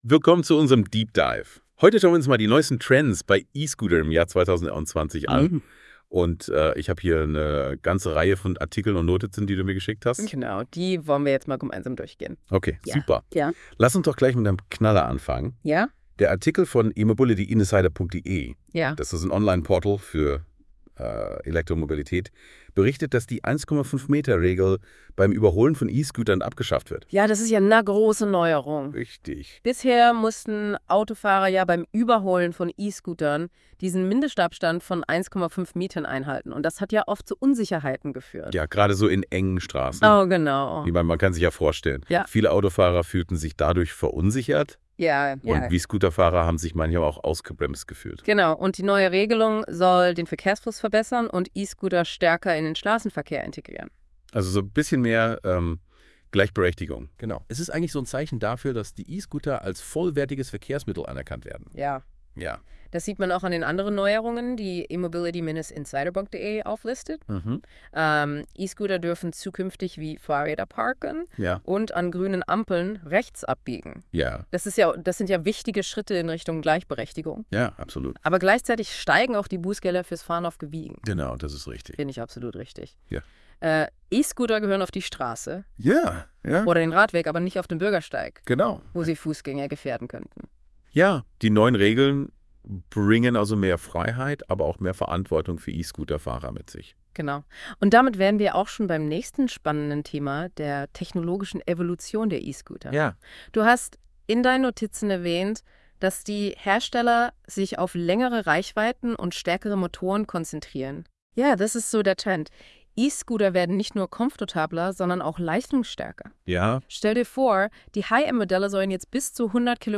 In unserem Experten Dialog erfahrt Ihr alle Insights die für 2025 wichtig sind.